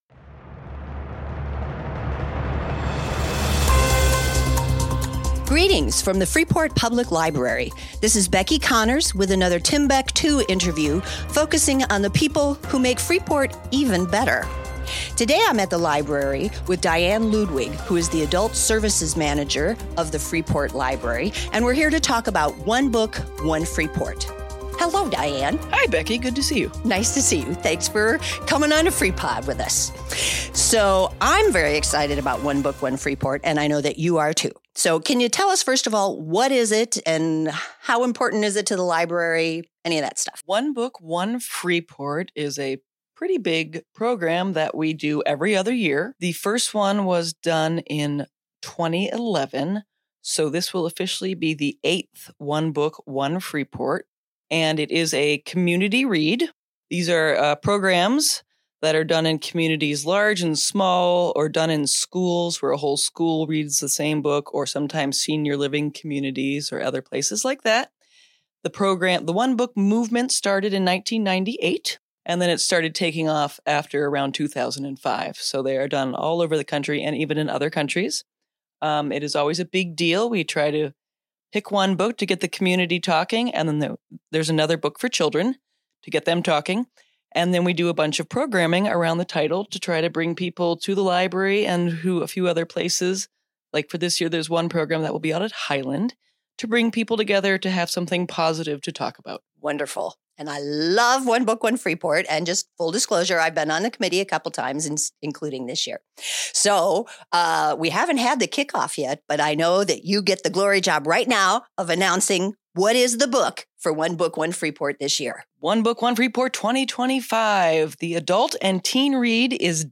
Freepod - Freepod Interview: One Book One Freeport